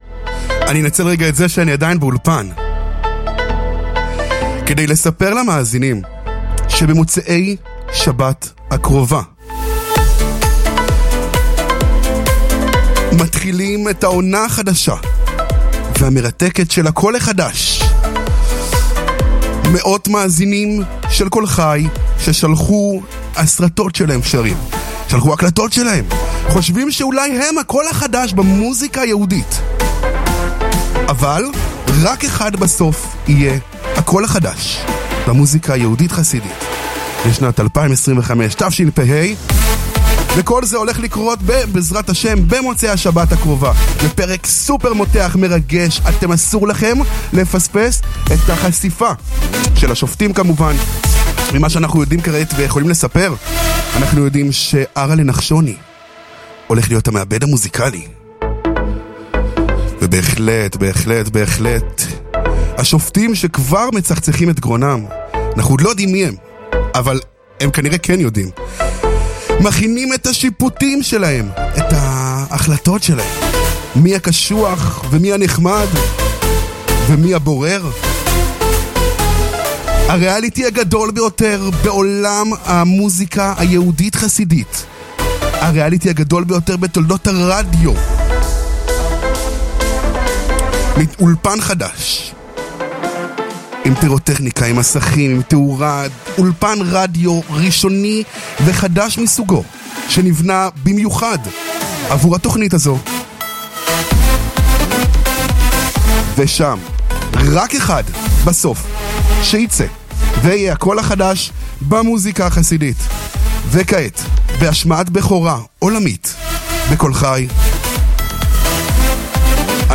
בראיון על הקול החדש